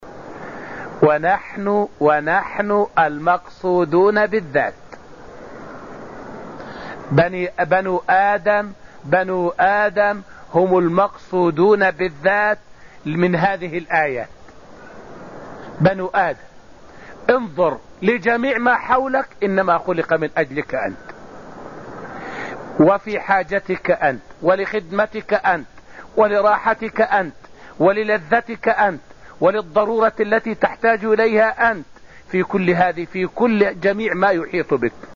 فائدة من الدرس الأول من دروس تفسير سورة الذاريات والتي ألقيت في المسجد النبوي الشريف حول أن كل ما في الكون مسخرٌ للإنسان.